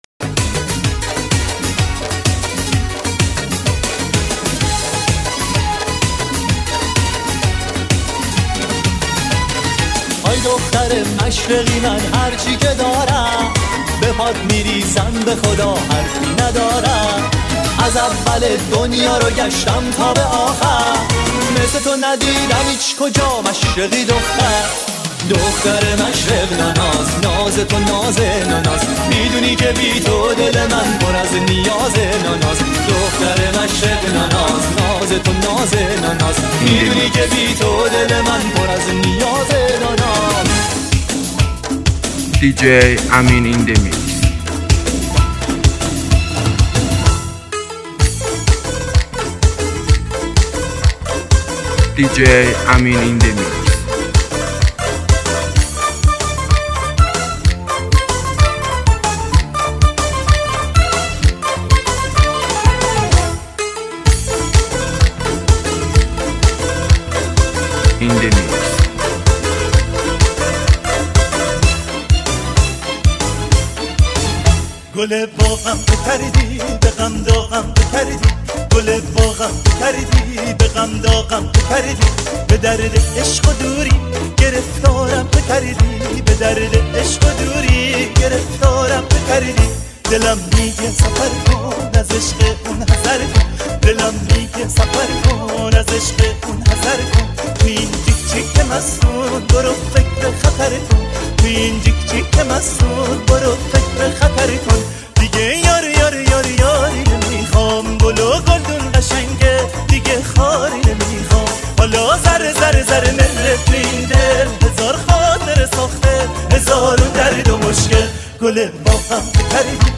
persian remix